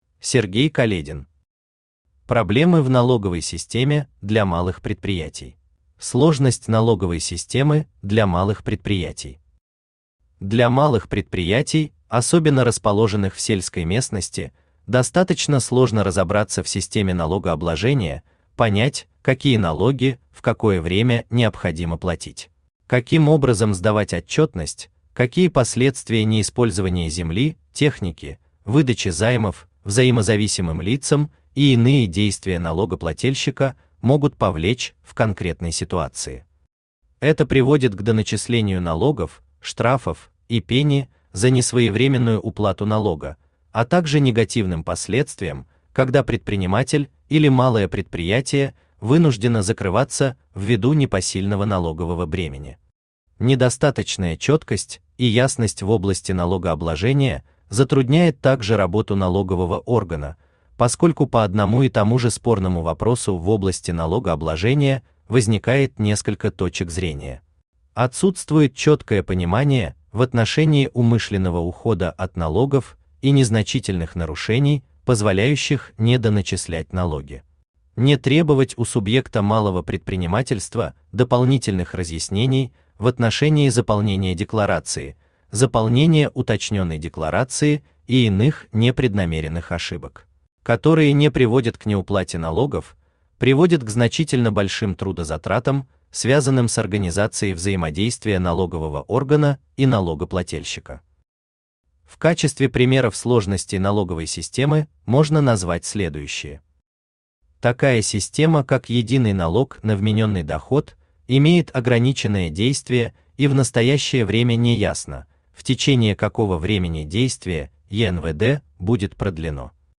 Аудиокнига Проблемы в налоговой системе для малых предприятий | Библиотека аудиокниг
Aудиокнига Проблемы в налоговой системе для малых предприятий Автор Сергей Каледин Читает аудиокнигу Авточтец ЛитРес.